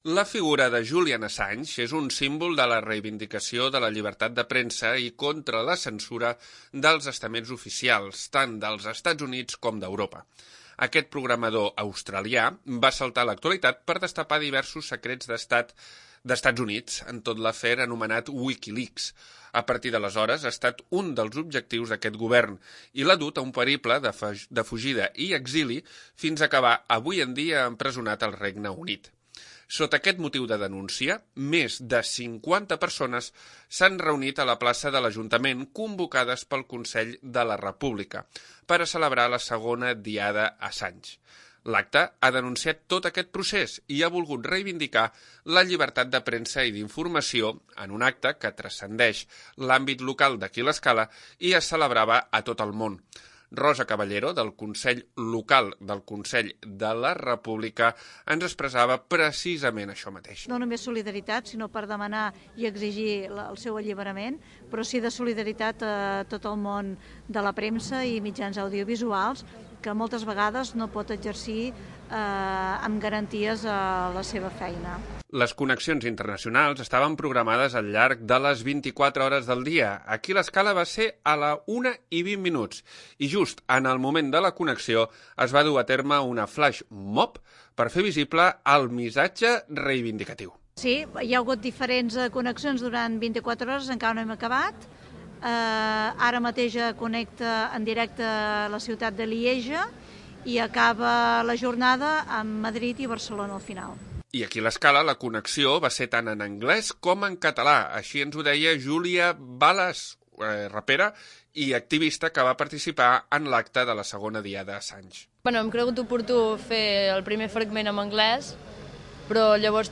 Sota aquest motiu de denúncia, més de 50 persones s'han reunit a la Plaça de l'Ajuntament, convocades pel Consell de la República, per a celebrar la 2a Diada Assange. l'Acte ha denunciat tot aquest procés i ha volgut reivindicar la llibertat de premsa i d'informació, en un acte que trascendeix l'àmbit local i es celebrava a tot el món.